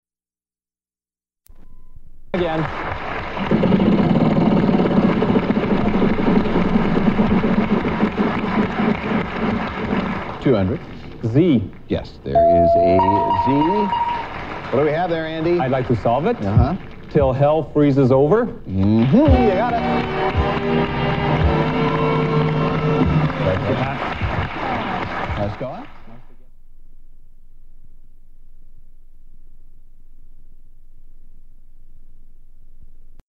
Excerpt from a "Wheel of Fortune" episode, where the puzzle is "'Til Hell Freezes Over"
• Audiocassette